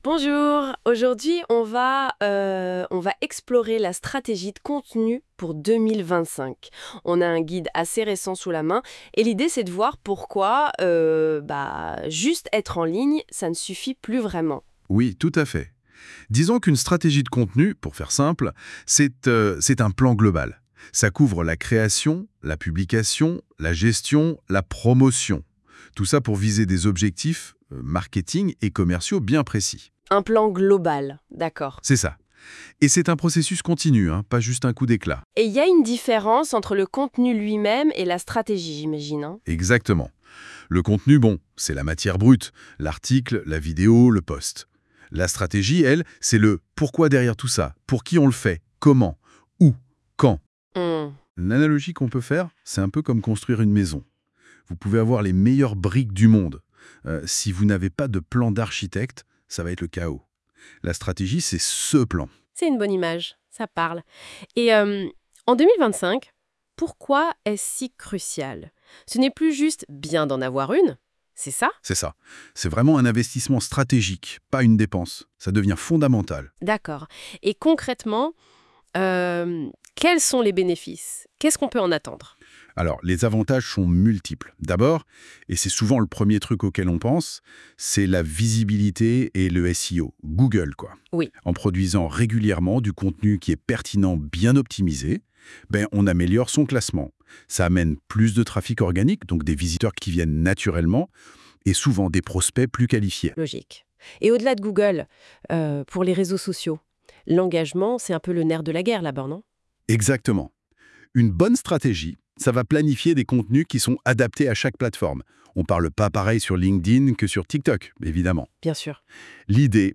Nous avons intégré un podcast généré automatiquement grâce à NotebookLM, un outil d’intelligence artificielle. Cette version audio vous permet de découvrir notre contenu autrement et comprendre l’importance d’une stratégie de contenu en 2025